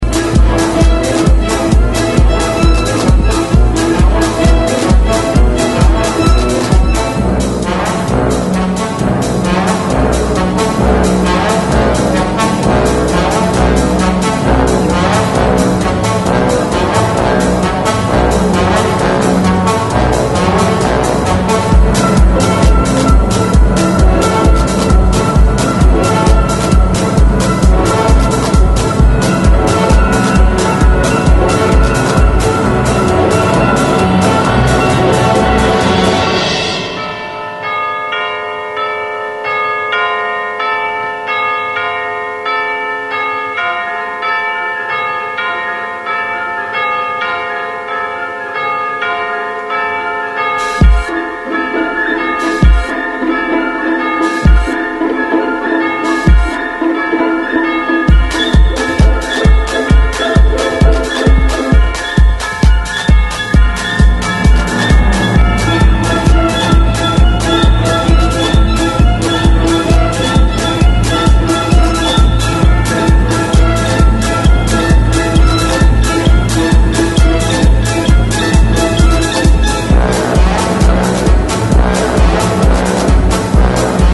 versión sinfónica más “refinada”
Todas las pistas están remasterizadas para la serie.